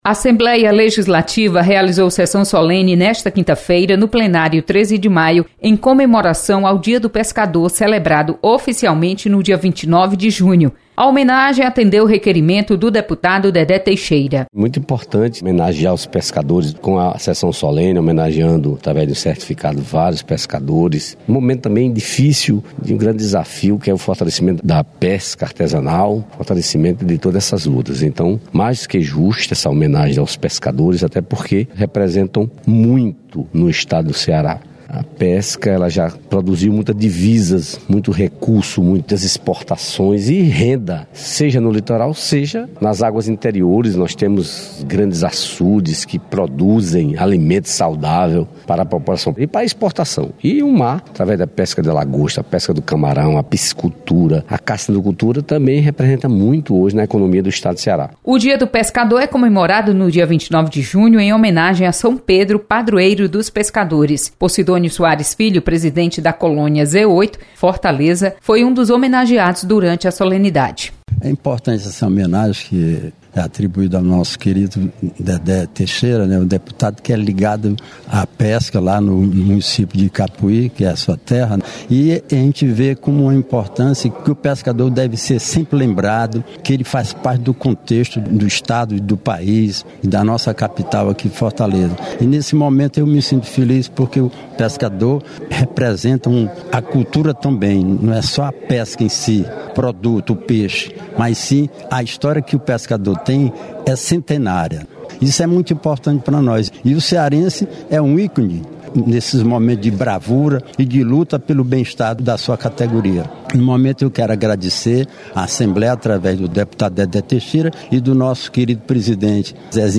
Sessão solene comemora o Dia do Pescador nesta quinta-feira. Repórter